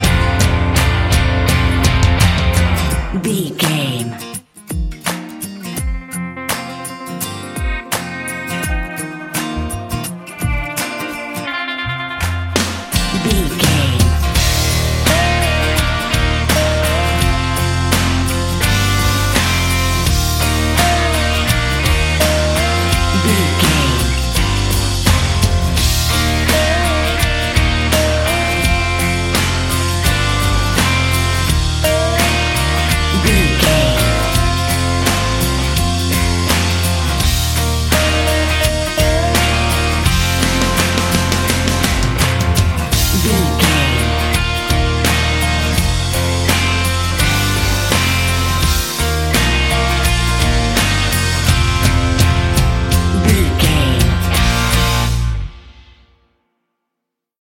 Ionian/Major
E♭
acoustic guitar
electric guitar
drums
bass guitar
Pop Country
country rock
bluegrass
happy
uplifting
driving
high energy